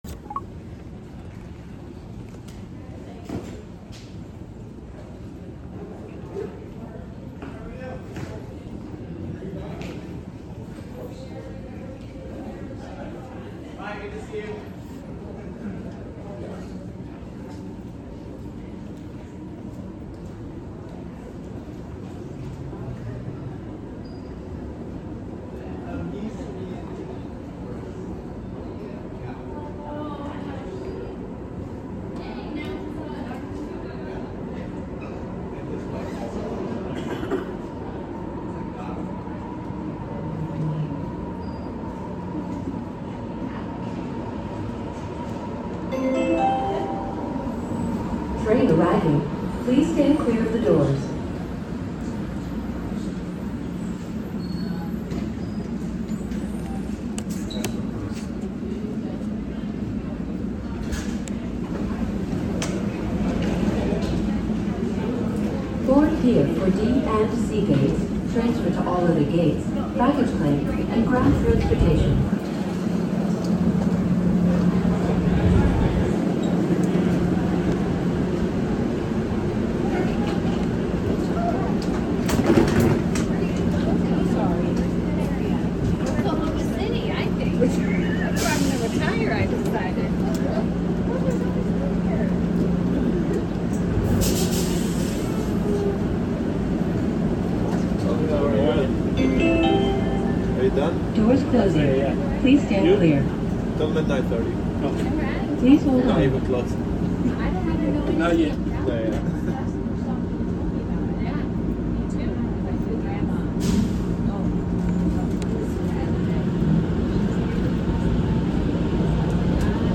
I rode the train at Seattle's airport.